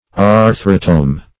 Search Result for " arthrotome" : The Collaborative International Dictionary of English v.0.48: Arthrotome \Ar"thro*tome\, n. [Gr.